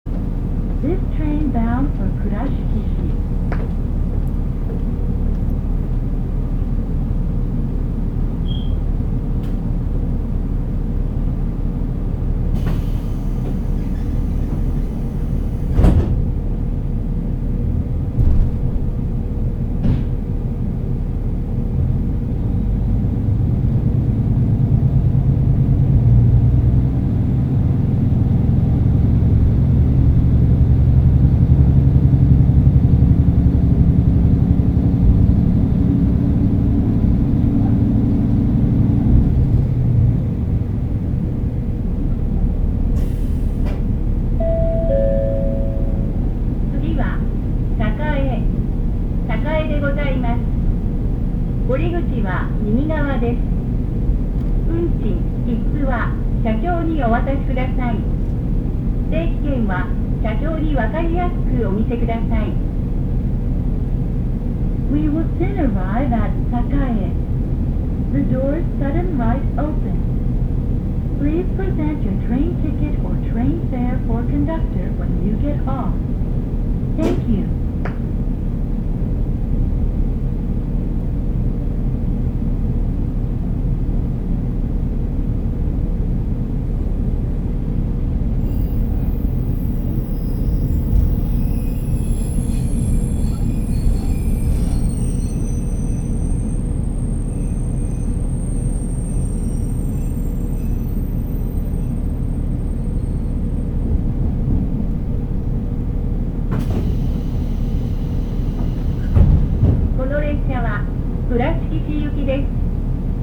走行音
録音区間：常磐～栄(お持ち帰り)